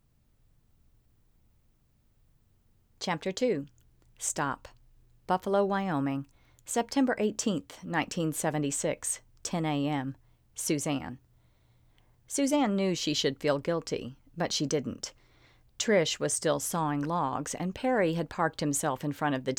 Otherwise, it’s 20 seconds of unedited audio, using the tips you’ve given so far and with the same settings and configuration I was using last week.
As almost a side issue, ACX is looking for those tricks and your clip clearly has theatrical silences between words that don’t match the room tone at the front.
And yes, just from casual sound analysis, it’s going to be an uphill battle to get these cleaned up.
The background sounds in the test before this one do not match.